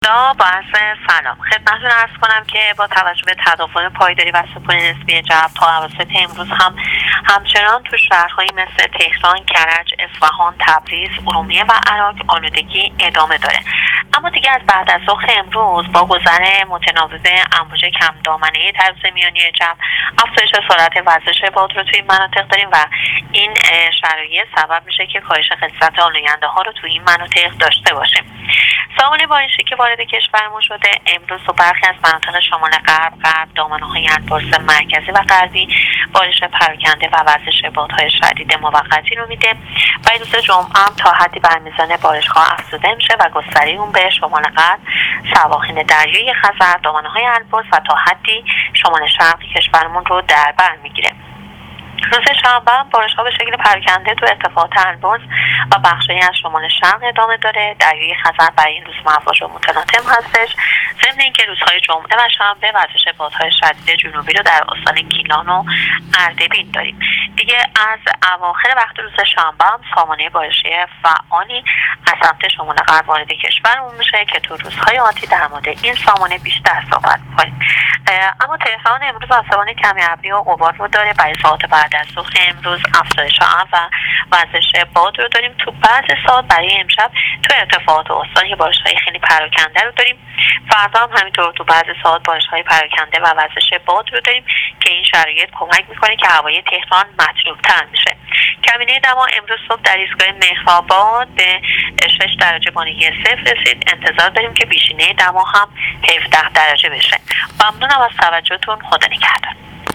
کارشناس سازمان هواشناسی در گفتگو با راديو اينترنتی پايگاه خبری آخرين وضعيت هوا را تشریح کرد.